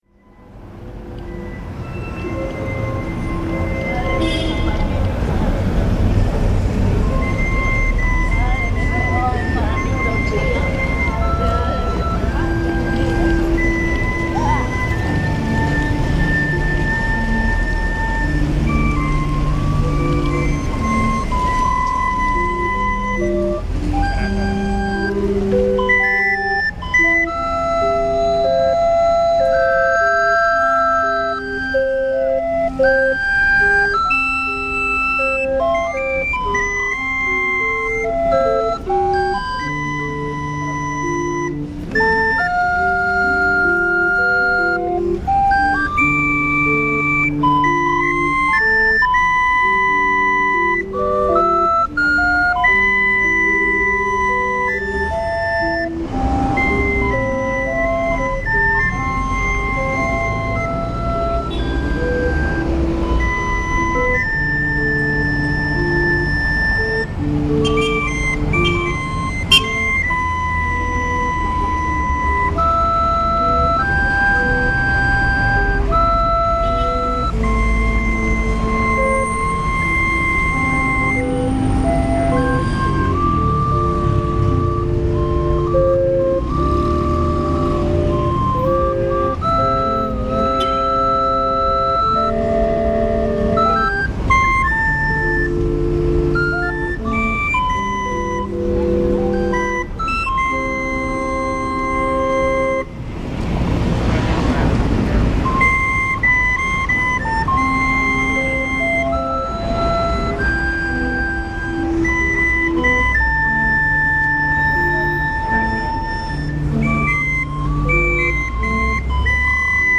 Organillero frente al Museo de la Ciudad
Despues de asistir a una conferencia de prensa del proyecto de Germinalia A.C. para rescatar El Bosque escuche en la calle a un organillero, así que decidí grabarlo para compartirlo con ustedes, espero les guste.
Fecha de grabación: 29 de mayo de 2014 Hora: 10:00 horas aproximadamente Lugar: Frente al Museo de la Ciudad de Tuxtla Gutierrez, Chiapas. Mexico Equipo: Grabadora digital Sony ICD-UX80, micrófono de construcción casera.